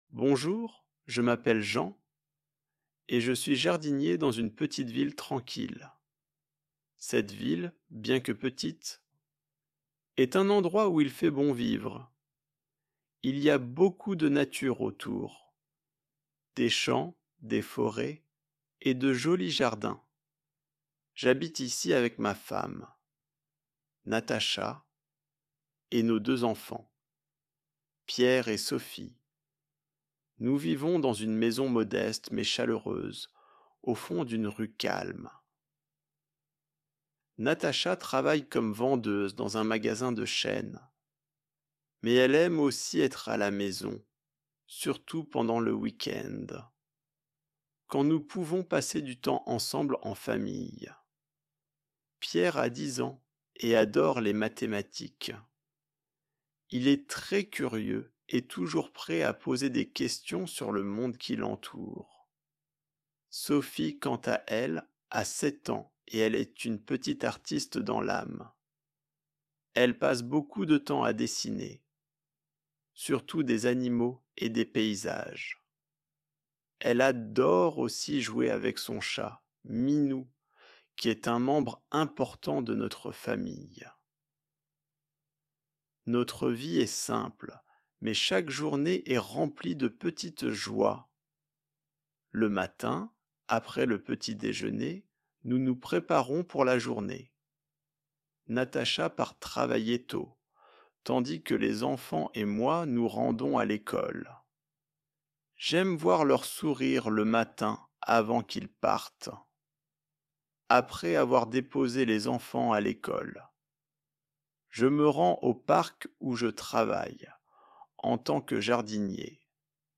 Améliorez votre français avec une histoire facile et lente !
Lis & Écoute en Slow French